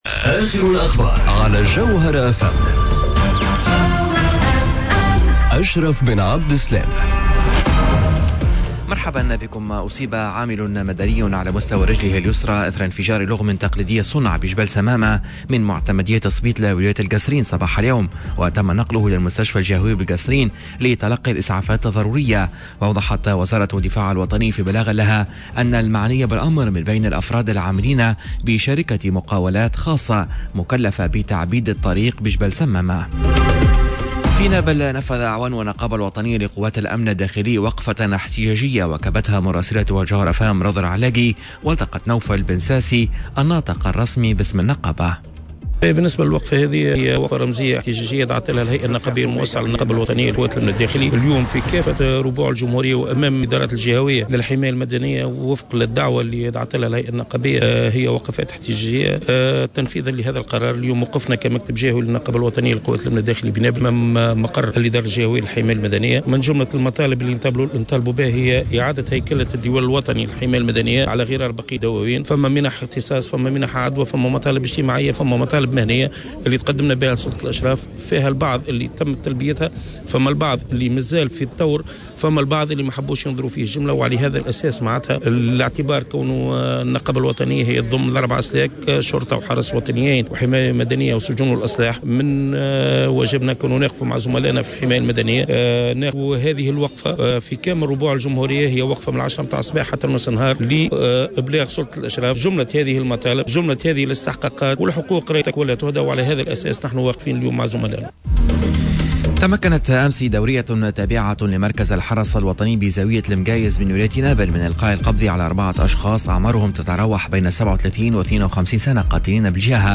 نشرة أخبار منتصف النهار ليوم الخميس 14 مارس 2019